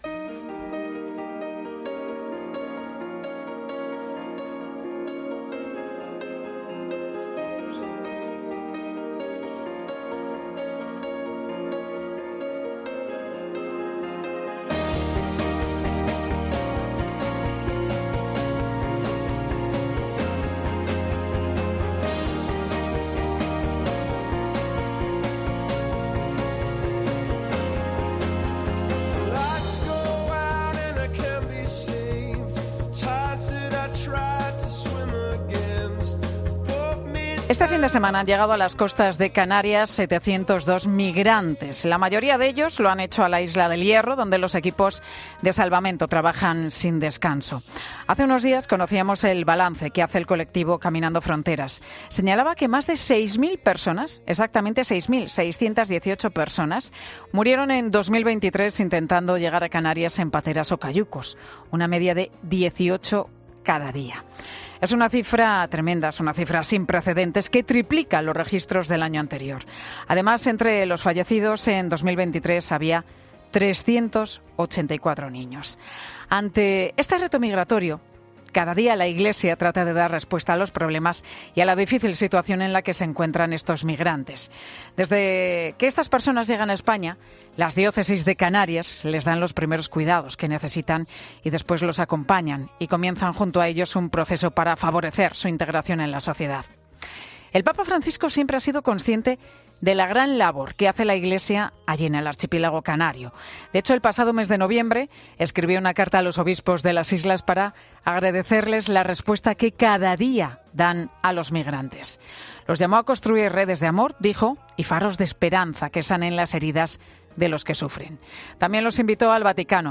El obispo de Canarias muestra en 'Mediodía COPE' su satisfacción por la recepción del Papa a los tres obispos de las diócesis canarias: "Lo que nos...